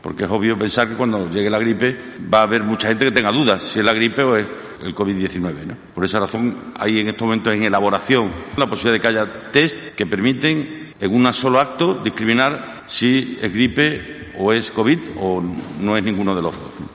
AUDIO: Fernández Vara en rueda de prensa tras la última videoconferencia de ptes autonómicos. Foto: Juntaex